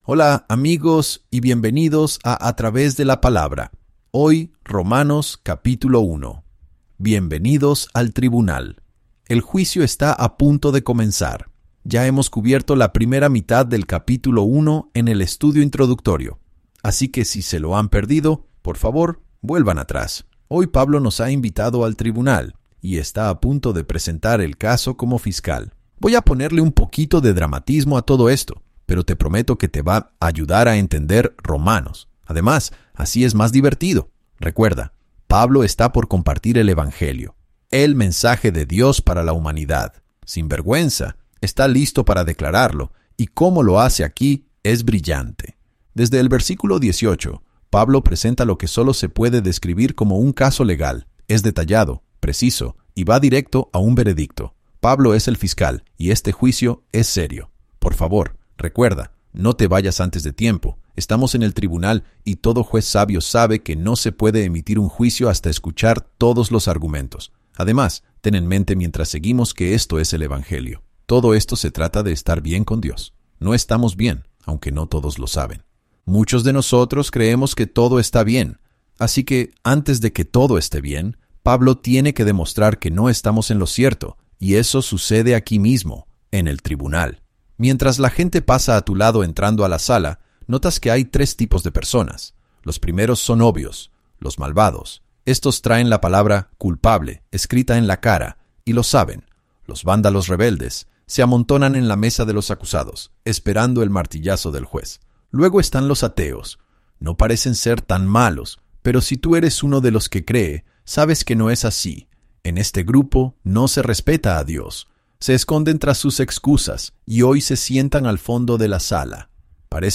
Cada capítulo aporta nuevas perspectivas y comprensión mientras tus maestros favoritos explican el texto y hacen que las historias cobren vida.